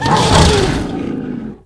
c_croccata_hit2.wav